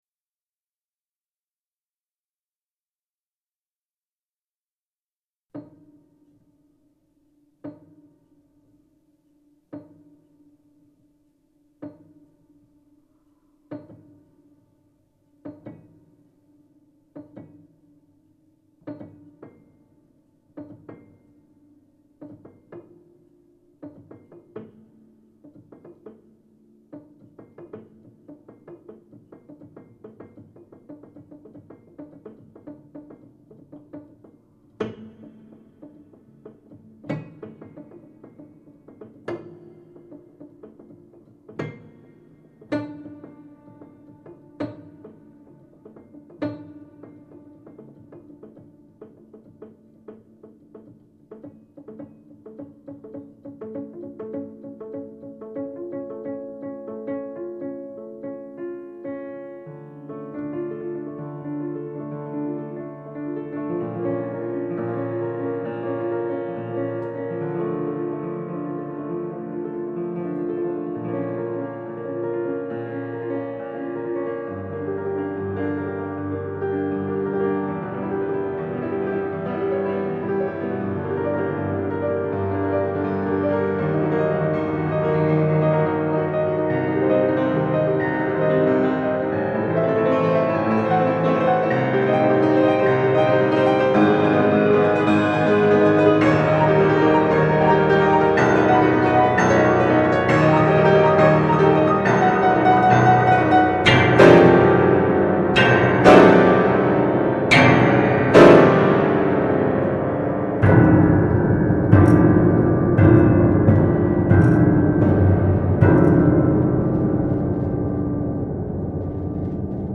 in the Clarisse Auditorium, the Province of Genoa offered the Symposium participants a private concert
An extraordinary musical piece for piano entitled
and performed by him that night as a world premiere.